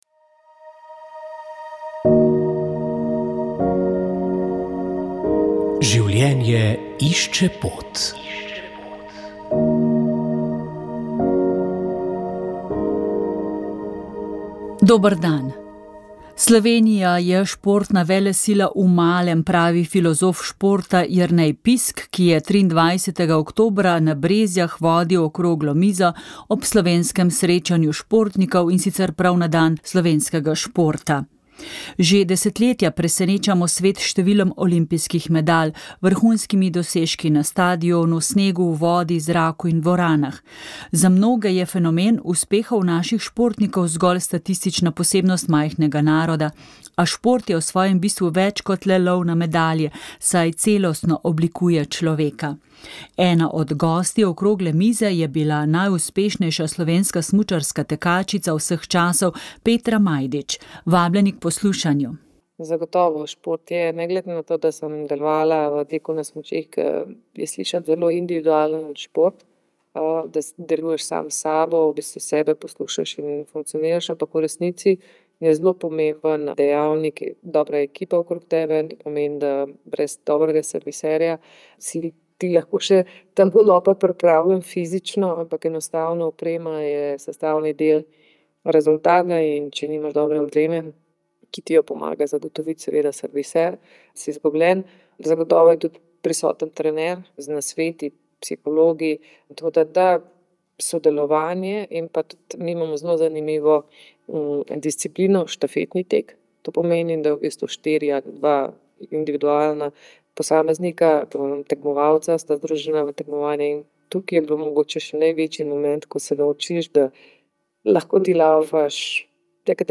Poslušate lahko še zadnjo, šesto oddajo iz znanstvene konference z naslovom Med tradicijo in moderno: slovenski katoliški intelektualci in narodnostno vprašanje.